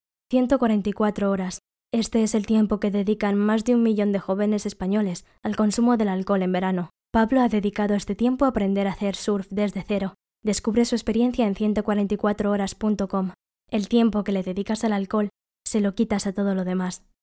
Locutora y actriz de doblaje.
Voz jóven, cercana y natural.
kastilisch
Sprechprobe: Industrie (Muttersprache):
Young, close and natural voice.